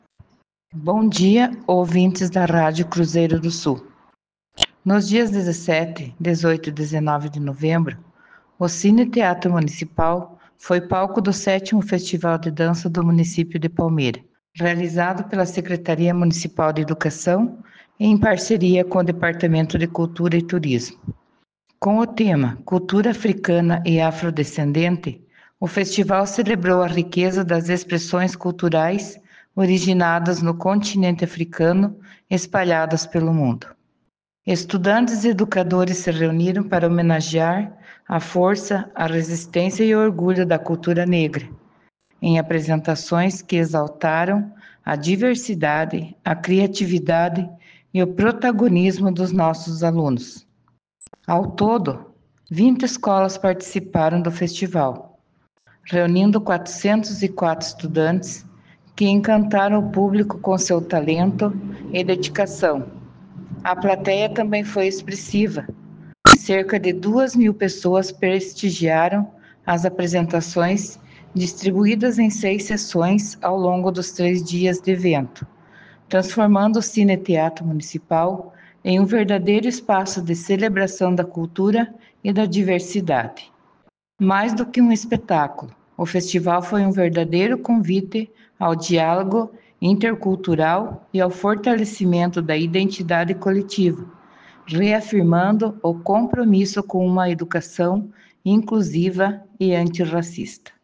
As apresentações dos grupos representantes das escolas do município aconteceram no palco do Cine Teatro Municipal e quem fala sobre o evento é a secretária de Educação, professora Dirlene Aparecida Delfrate.